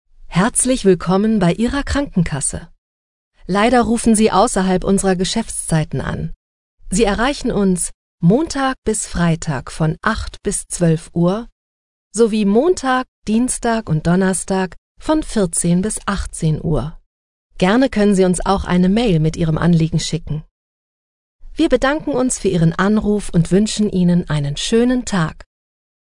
Natürlich, Cool, Vielseitig, Warm
Telefonie
The sound of her voice is middle-aged (about 30-50 years), warm, dynamic and changeable.